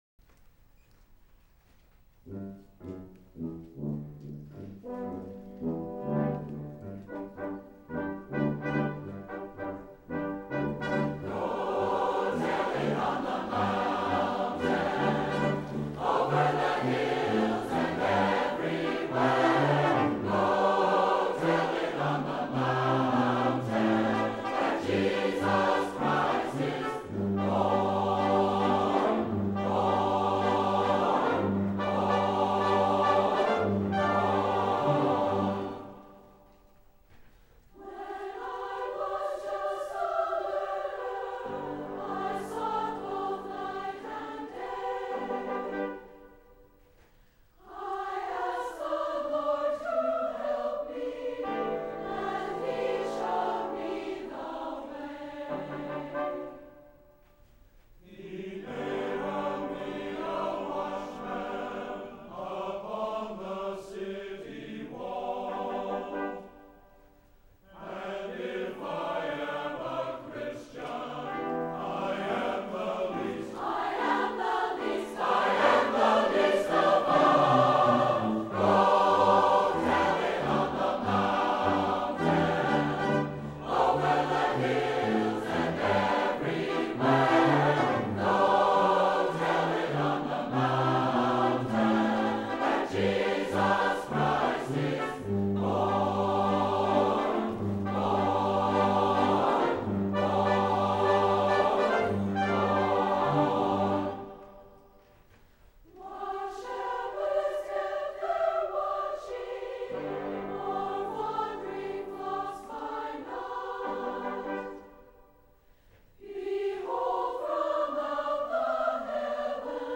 The refrains are at a lively tempo of 96.
SATB version